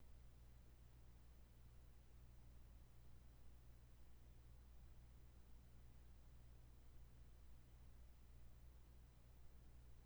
No extra crap from me, just “silent” room noise with the ac running.
Oh yes, however distant, I can still hear it.